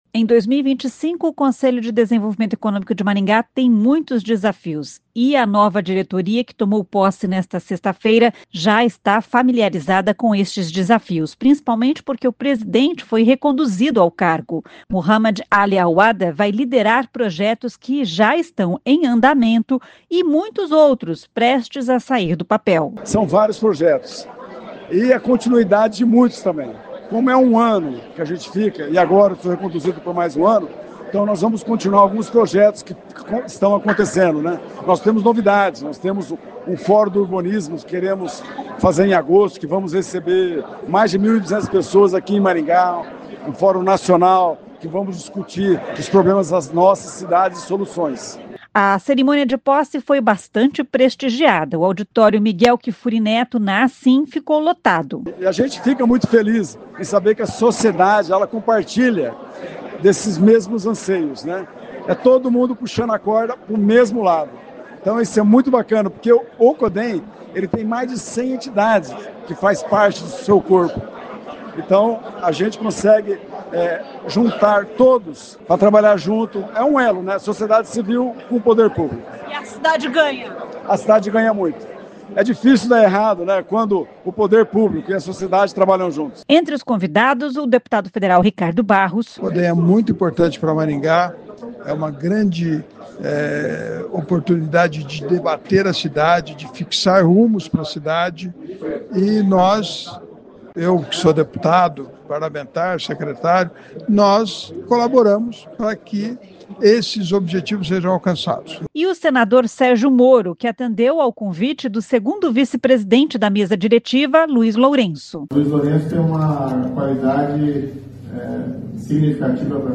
A cerimônia de posse foi bastante prestigiada.